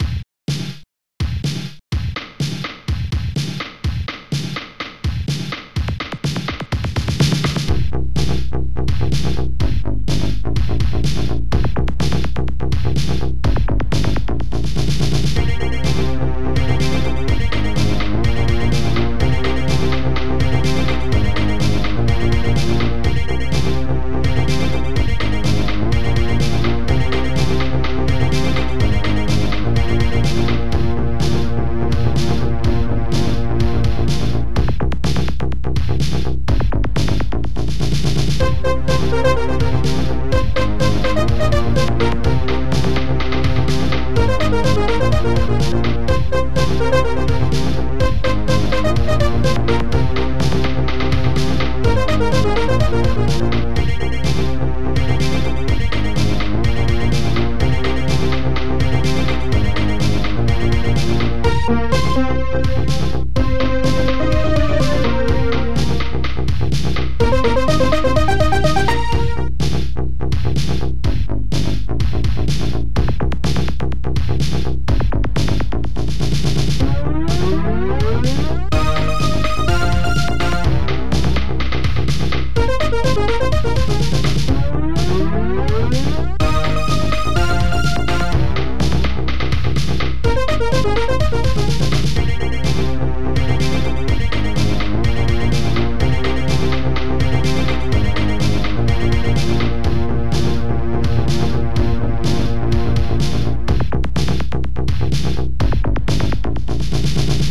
st-04:sp12bassdrum
st-04:sp12snare
st-01:dxbass
st-01:strings4
st-04:echotrump